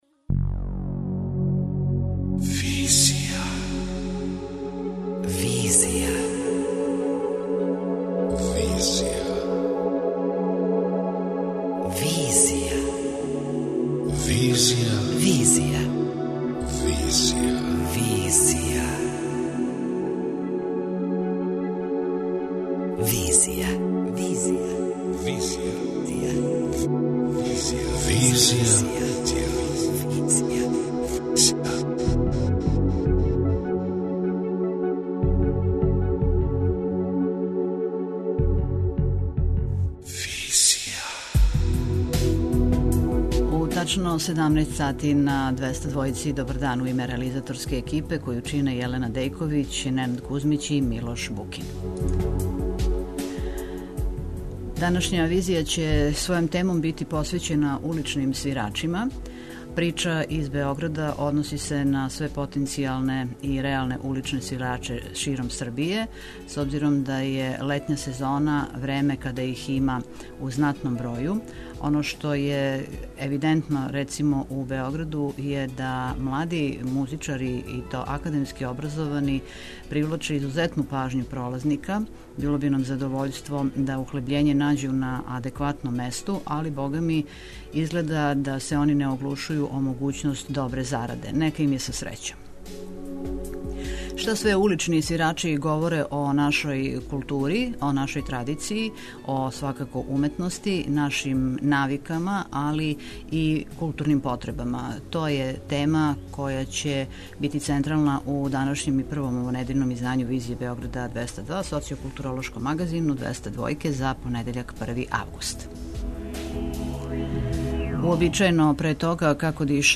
У овој причи, чућемо и утиске самих уличних уметника: како проводе дане и зашто је, осим зараде, улица место одличне самопромоције.